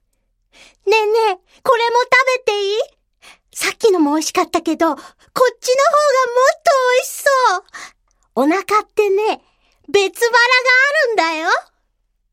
セリフ2
ボイスサンプル